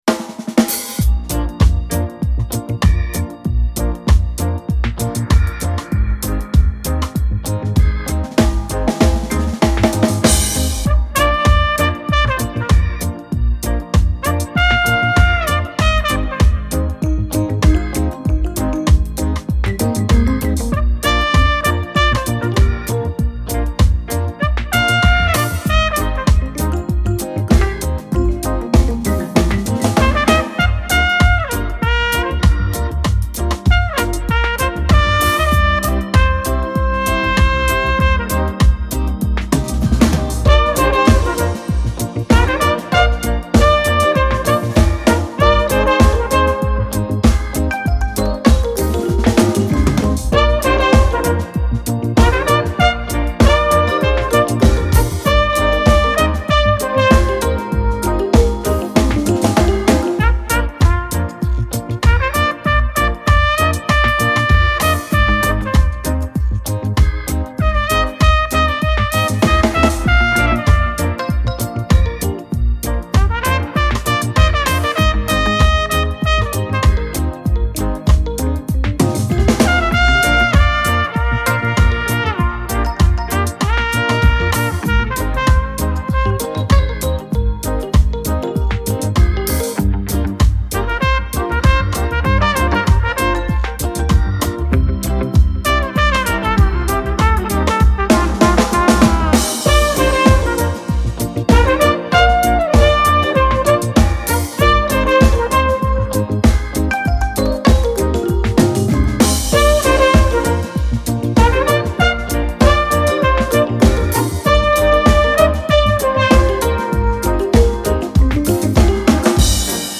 Genre : Contemporary Jazz